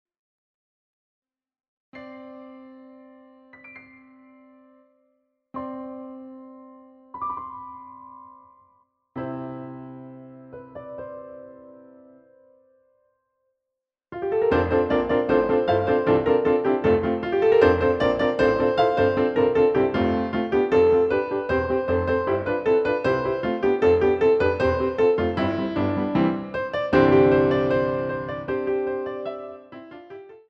using the stereo sa1mpled sound of a Yamaha Grand Piano.